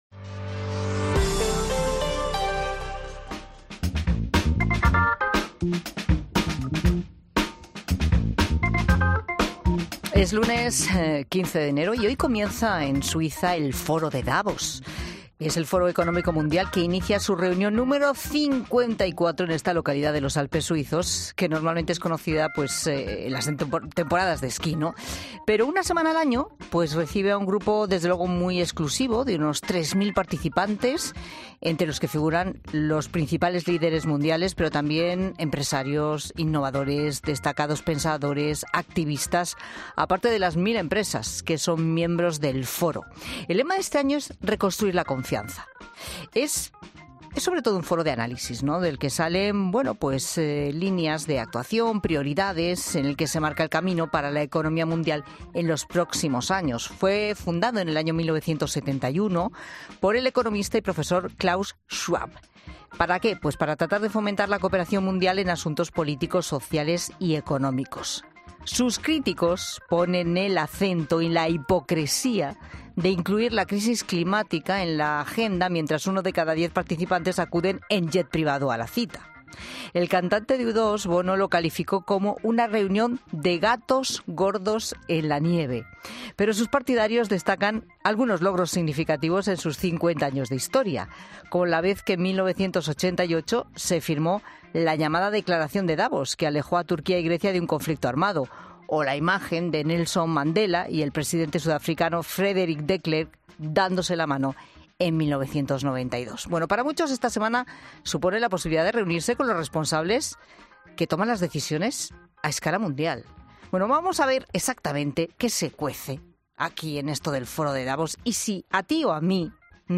El economista Fernando Trías de Bes analiza el Foro de Davos: ¿por qué debería importarnos?